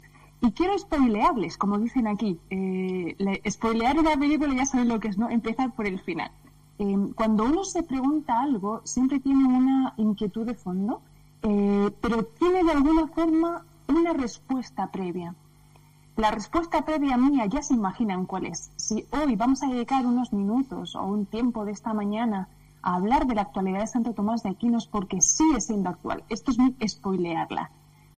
La ponencia ha sido vía internet y sin alumnos en el salón de actos, como es tradicional en otras ediciones.